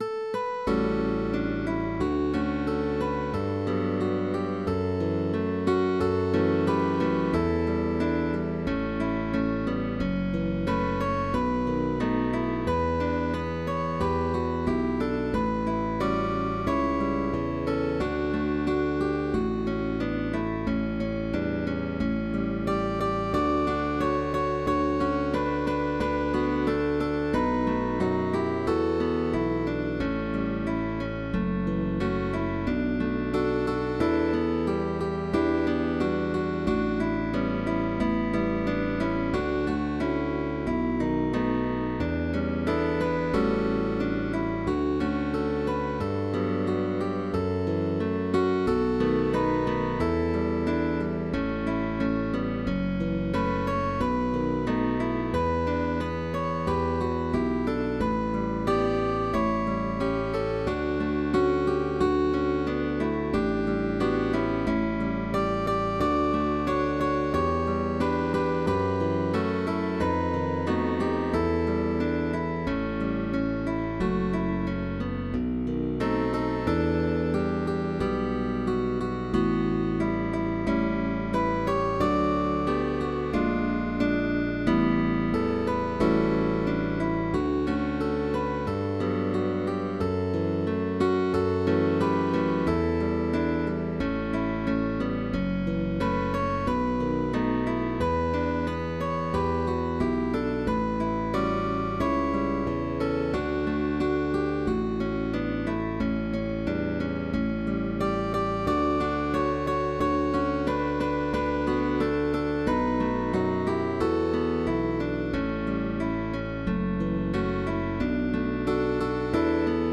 Sheet music for guitar trio.
classic bolero